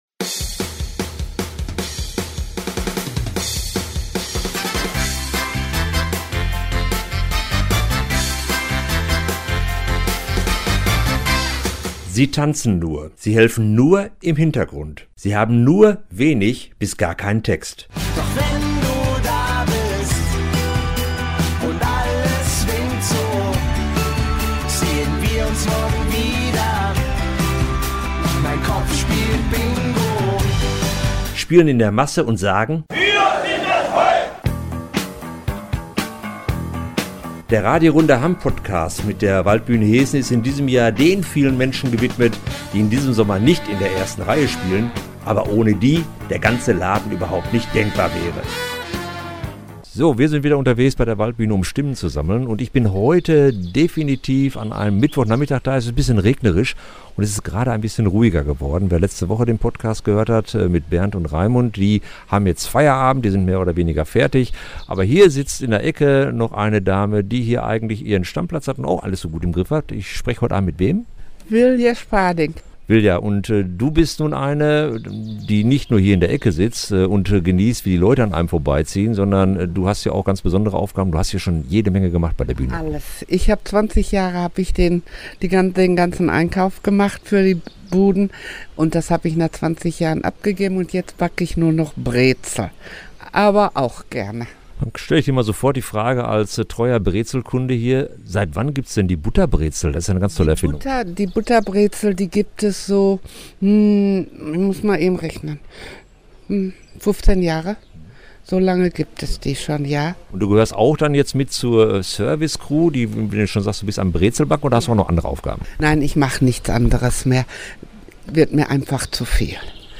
News Startseite Podcast